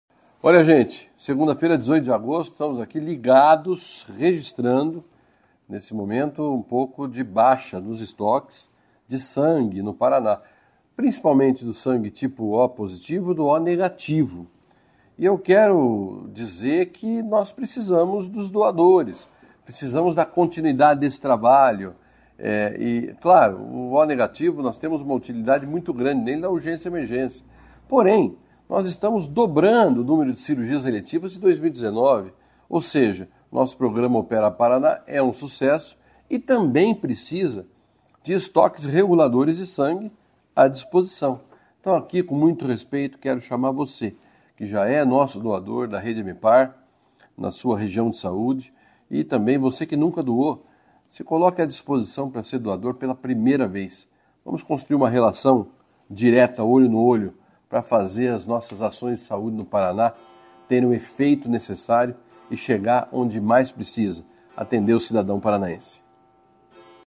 Sonora do secretário Estadual da Saúde, Beto Preto, sobre a necessidade de doadores de sangue O- e O+ no Hemepar